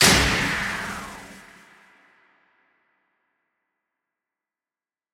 SouthSide Stomp (1) .wav